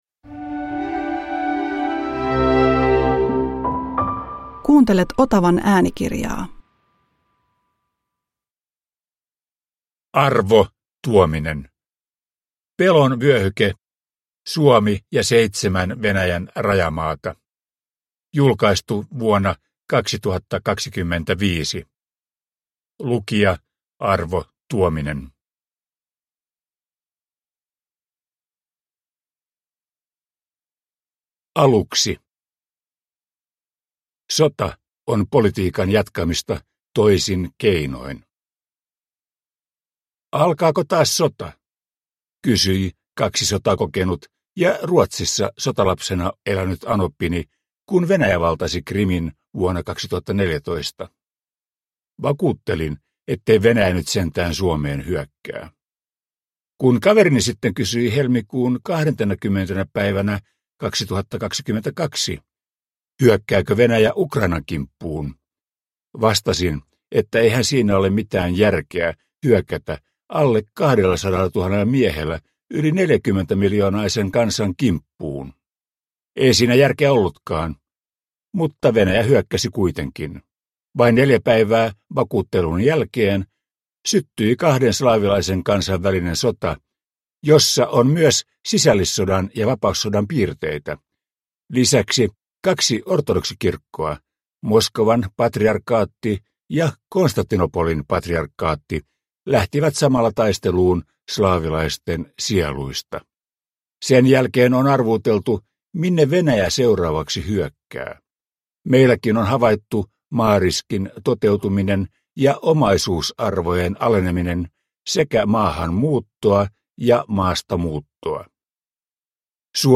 Pelon vyöhyke – Ljudbok